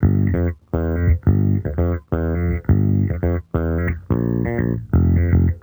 Index of /musicradar/sampled-funk-soul-samples/85bpm/Bass
SSF_JBassProc2_85G.wav